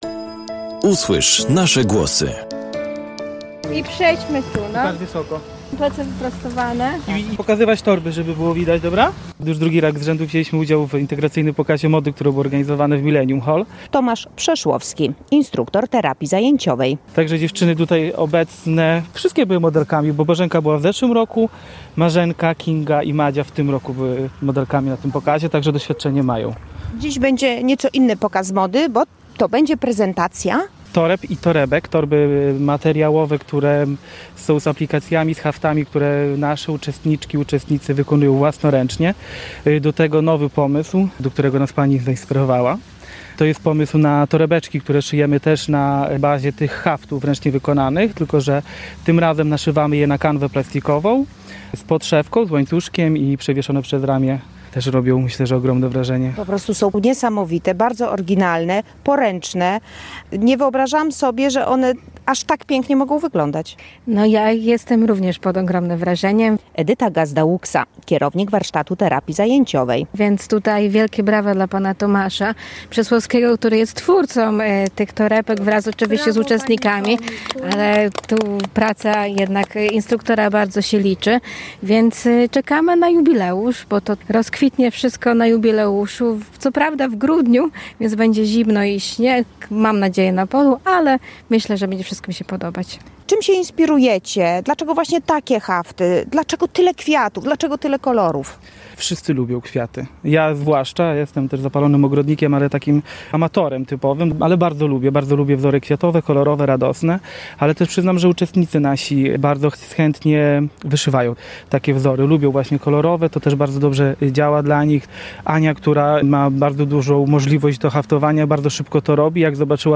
Specjalnie dla Polskiego Radia Rzeszów uczestnicy Warsztatu Terapii Zajęciowej przy ul. Załęskiej w Rzeszowie przygotowali mini pokaz mody, podczas którego zaprezentowali swoje najnowsze dzieła. Są to torby i torebki ozdobione ręcznymi haftami wykonanymi przez uczestników.